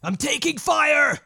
Voices / Male
Taking Fire.wav